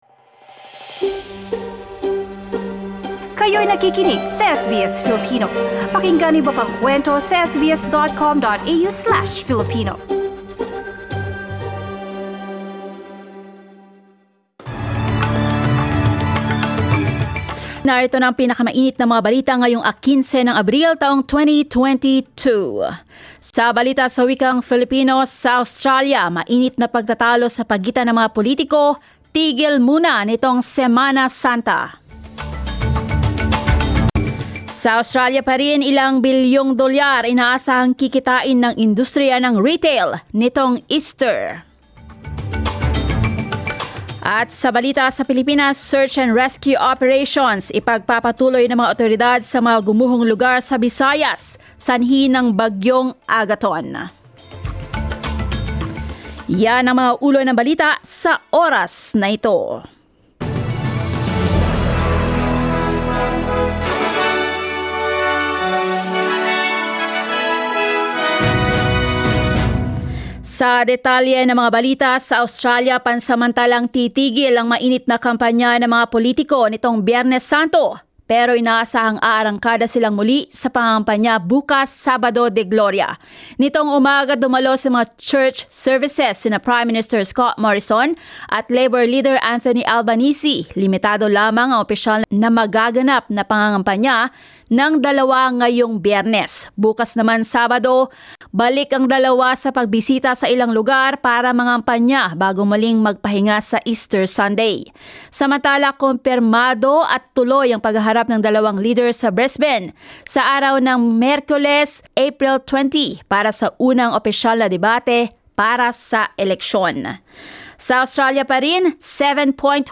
SBS News in Filipino, Friday 15 April